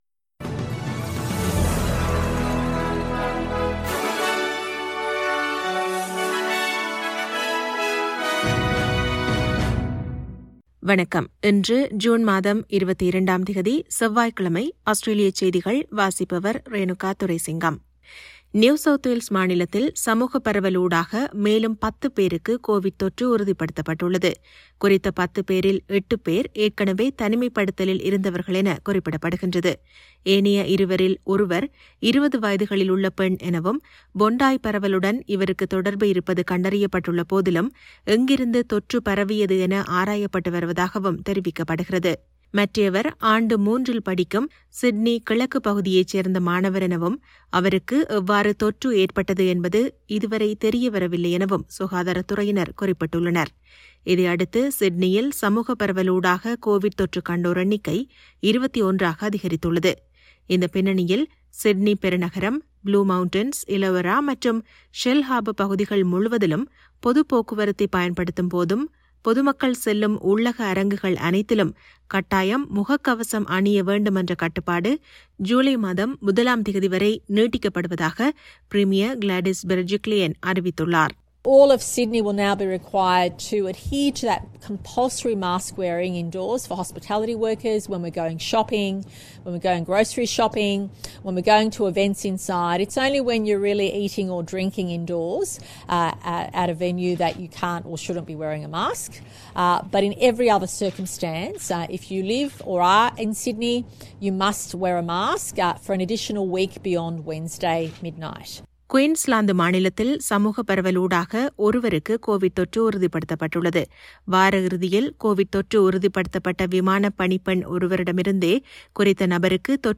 SBS தமிழ் ஒலிபரப்பின் இன்றைய (செவ்வாய்க்கிழமை 22/06/2021) ஆஸ்திரேலியா குறித்த செய்திகள்.